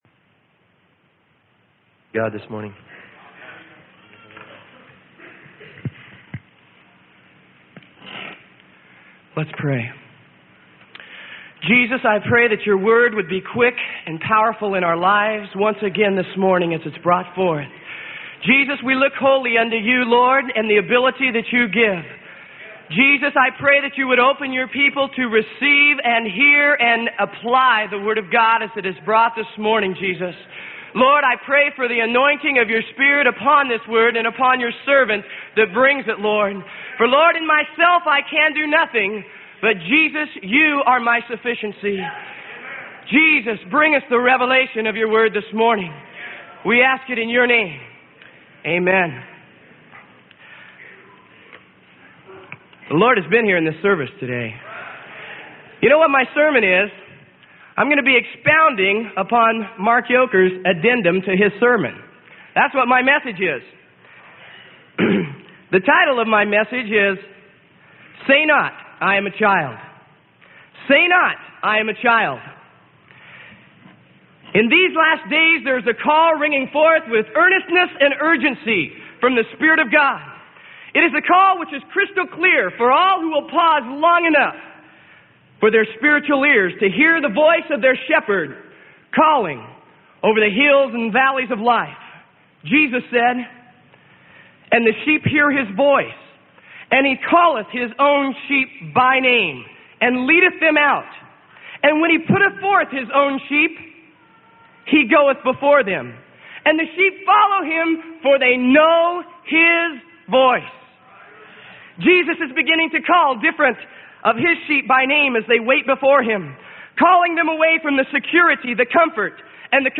Sermon: Say Not I Am a Child - Freely Given Online Library